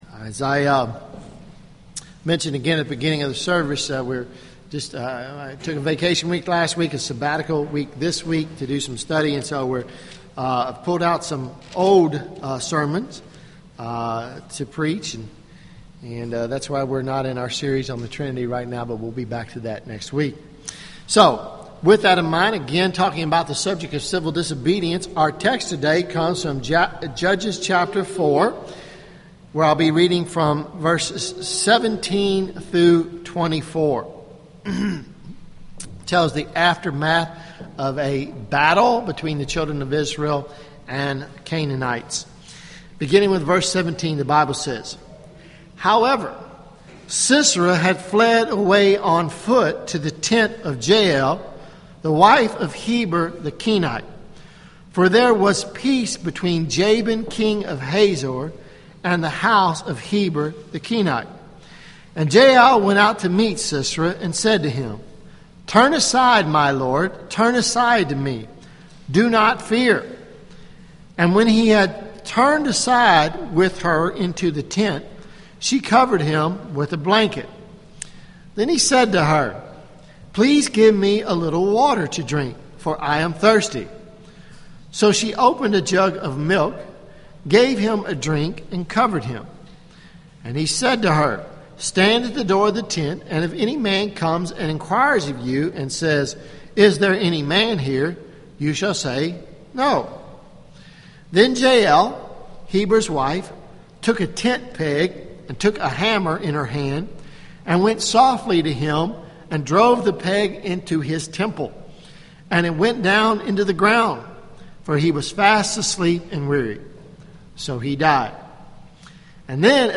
Sermons Jul 21 2013 Preached July 21